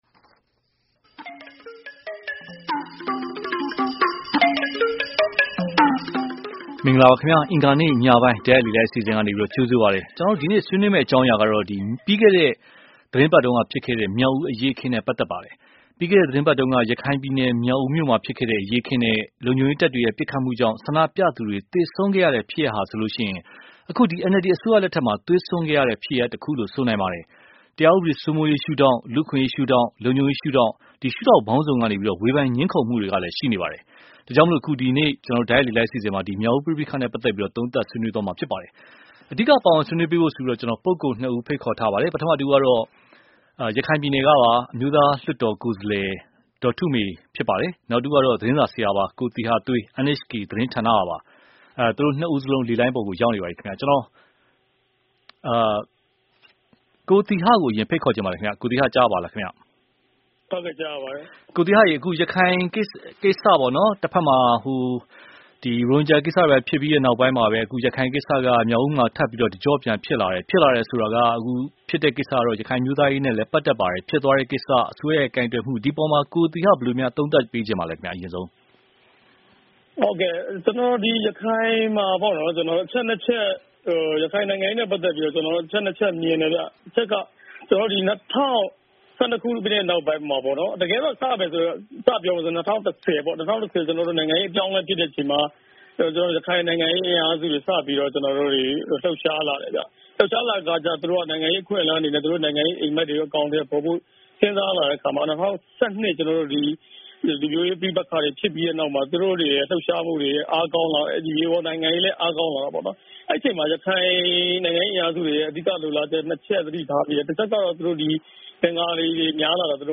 မြောက်ဦးအရေးအခင်နဲ့ အစိုးရကိုင်တွယ်မှု (တိုက်ရိုက်လေလှိုင်း)